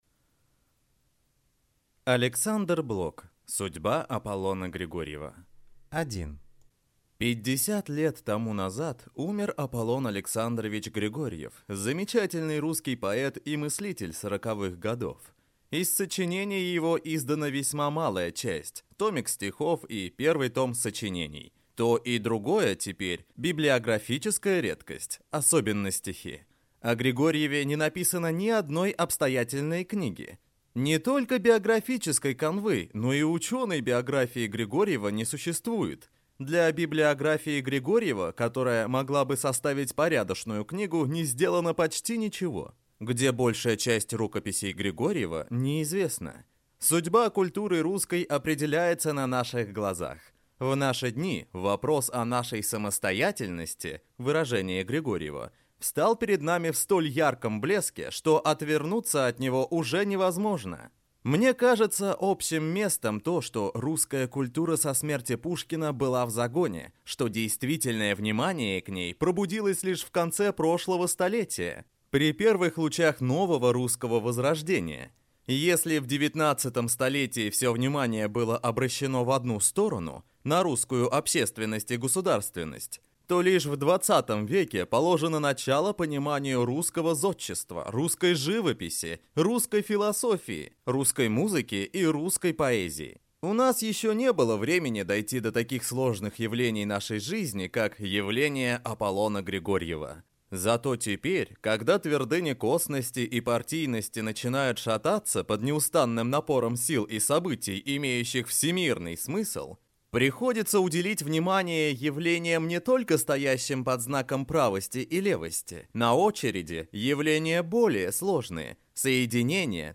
Аудиокнига Судьба Аполлона Григорьева | Библиотека аудиокниг